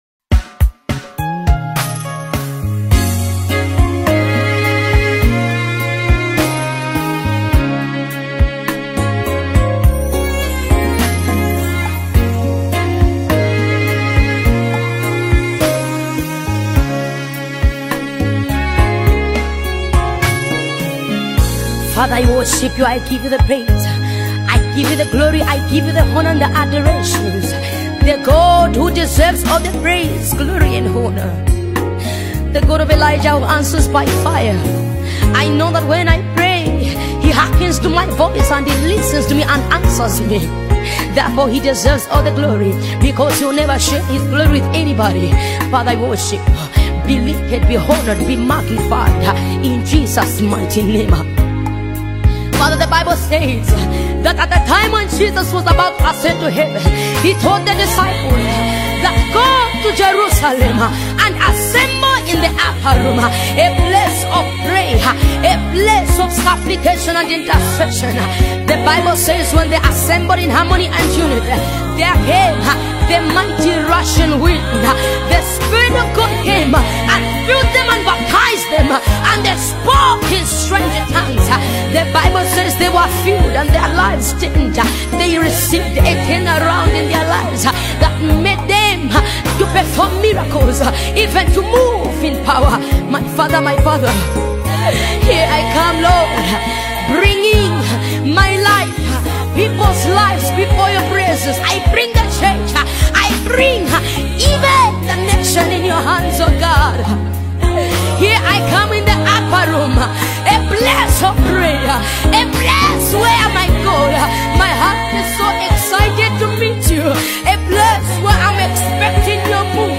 dynamic instrumentation